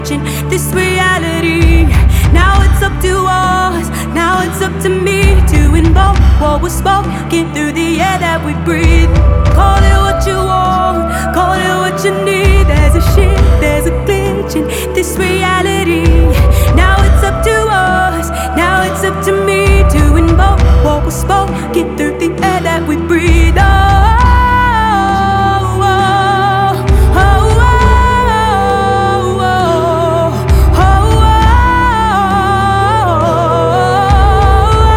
Жанр: Поп музыка / Альтернатива